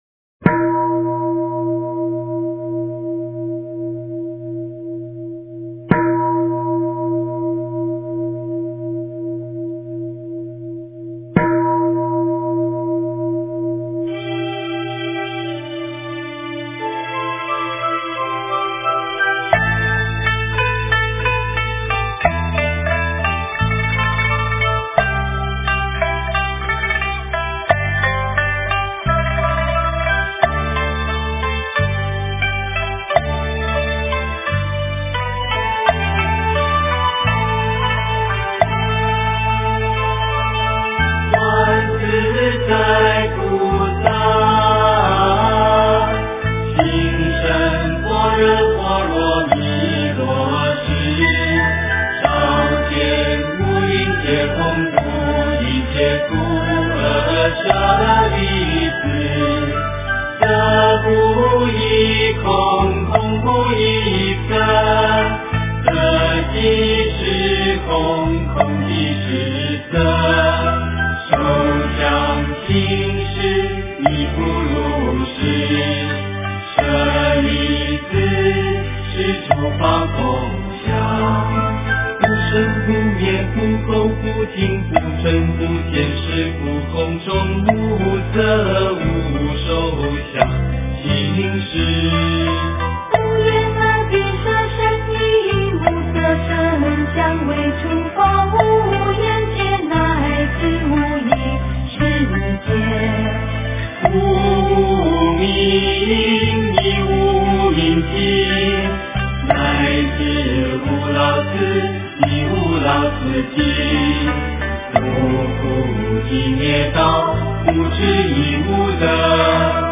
心经 诵经 心经--觉慧合唱团 点我： 标签: 佛音 诵经 佛教音乐 返回列表 上一篇： 报父母恩咒 下一篇： 心经 相关文章 《妙法莲华经》授记品第六 《妙法莲华经》授记品第六--佚名...